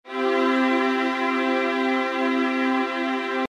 نمونه‌های صوتی یک بار با صدای سنتور و یکبار با صدایی ممتد اجرا شده‌اند.